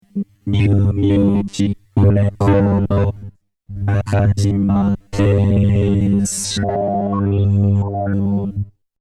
そう！ボコーダーなんです！こいつ。。。。
マイクに向かって「しゃべる」とその声がドミソの和音で出るです〜〜って言ってもよくわからないと思いますのでちょっと音を聞いて下さい。
あくまでも、マイクに向かって「しゃべる」声は普通です。歌わなくても話せばOK。鍵盤で弾けばその音階で声が変化して出て来ます。難しいですね〜〜